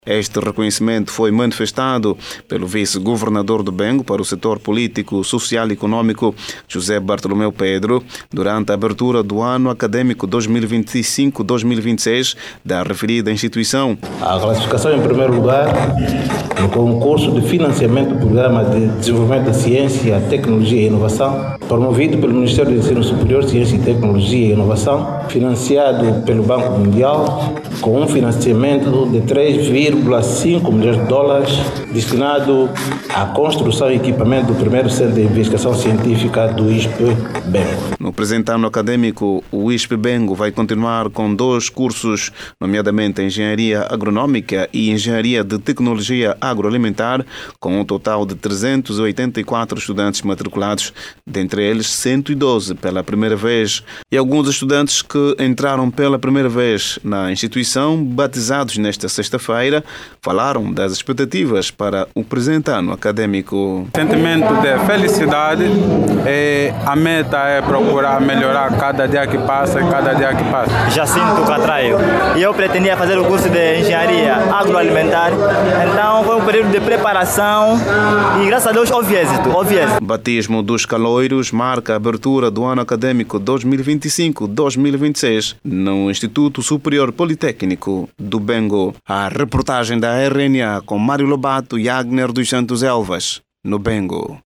Clique no áudio abaixo e saiba mais com o jornalista